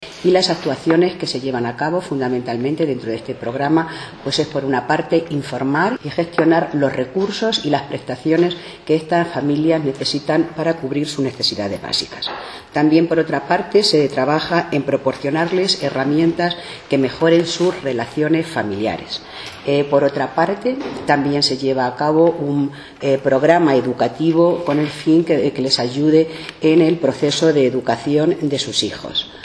Más de 400 personas se benefician de los programas relacionados con familia y adolescencia puestos en marcha por el Ayuntamiento de Valdepeñas, según ha destacado hoy en rueda de prensa, la Teniente alcalde de Servicios Sociales y Sanidad, Antonina Sánchez, que ha precisado que desde el área municipal se atienden a 143 familias de la localidad en dificultad social y económica, realizando además un seguimiento a 225 menores.